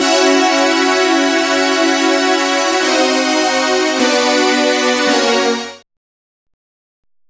Synth Lick 50-10.wav